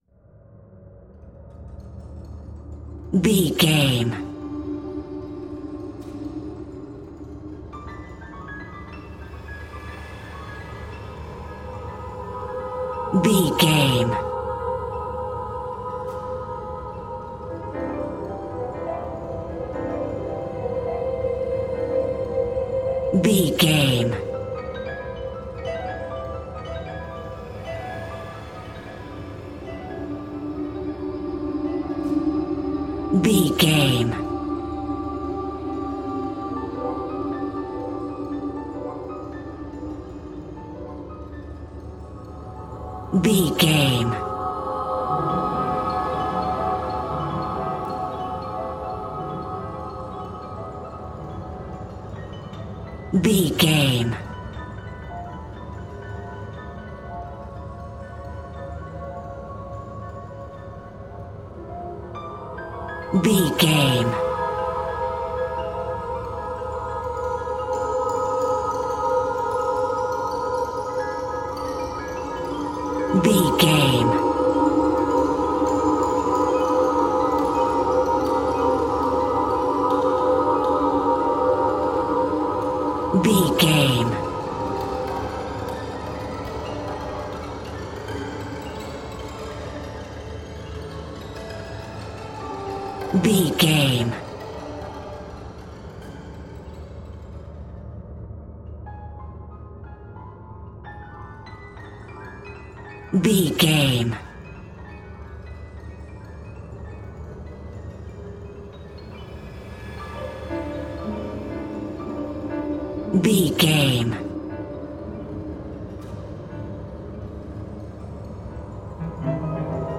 In-crescendo
Atonal
Slow
tension
ominous
haunting
eerie
strings
viola